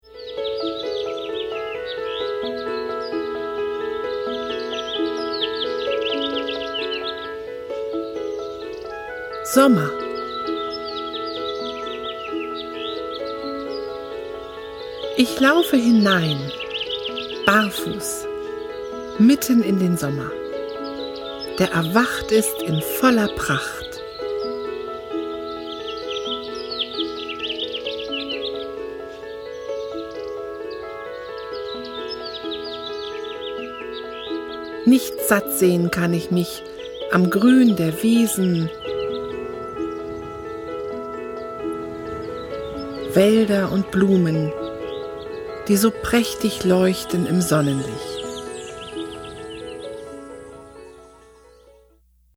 iPhone / iPad / iPod Vorschau Der Sommer (Ton)
Wir laden Sie ein auf eine meditative Reise mit einfühlsamen Texten und wunderschöner Musik durch die vier Jahreszeiten!
Sommer in der Natur- Sommer Sound